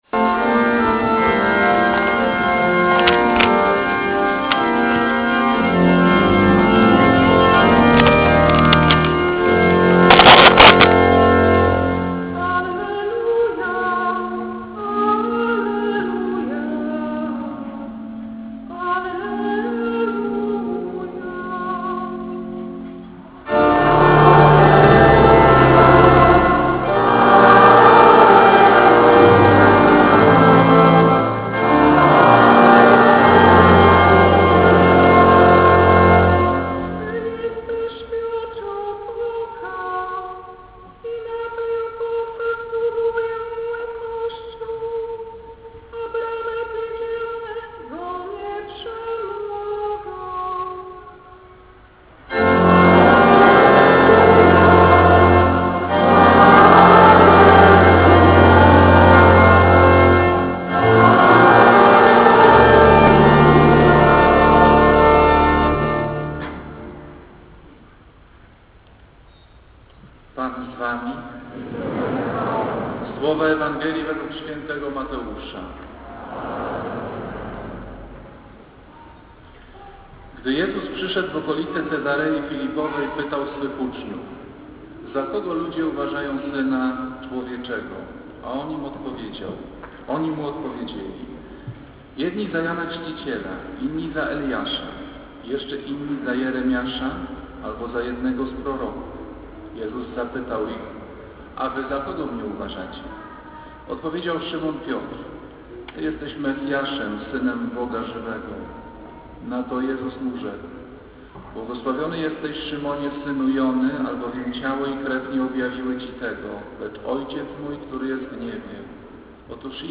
Kazanie z 21 sierpnia 2005r.
niedziela, godzina 15:00, kościół św. Anny w Warszawie « Kazanie z 15 sierpnia 2005r.